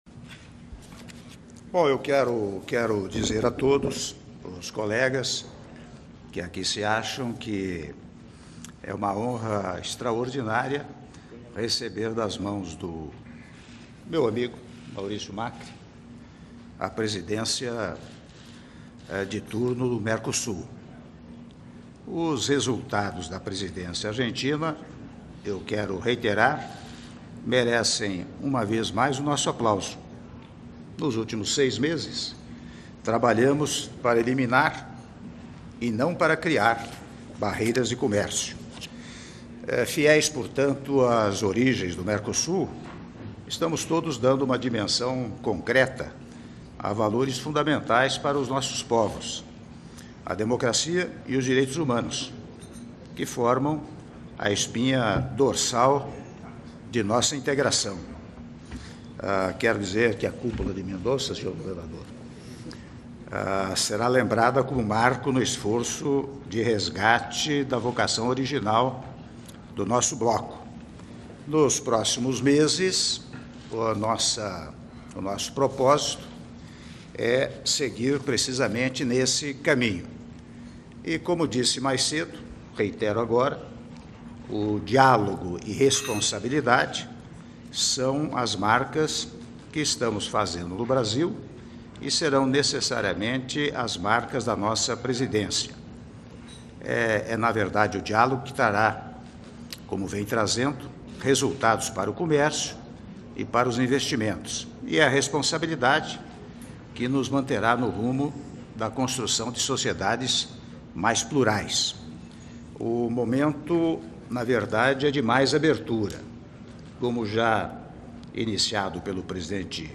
Áudio do discurso do Presidente da República, Michel Temer, na continuação da Sessão Plenária dos Senhores Presidentes dos Estados Membros do Mercosul, Estados Associados, México e Convidados Especiais - Mendoza/Argentina (06min25s)